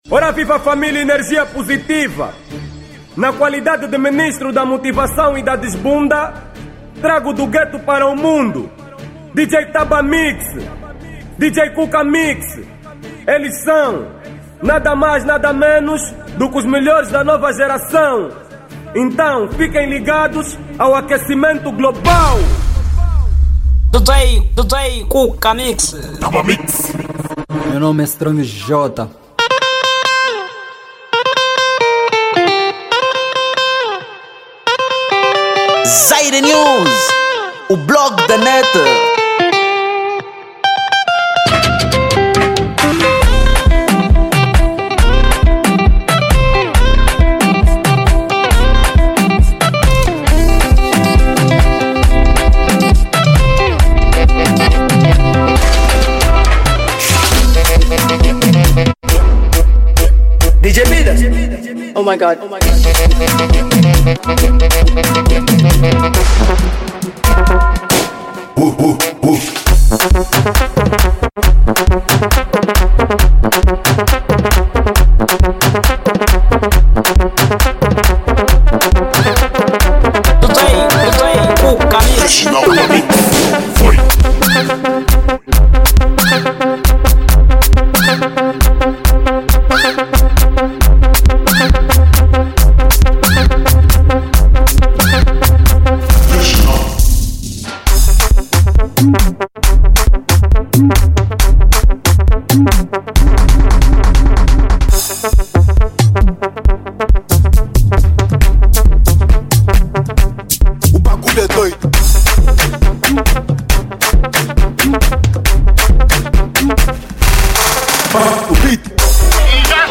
Género: mix Afro House